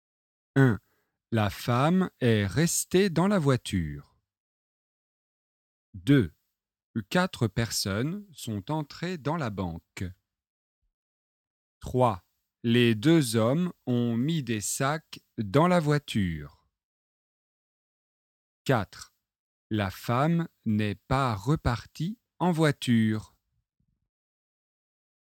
dialogue
au commissariat :  Situation 1 - situation 2 - situation 3- situation 4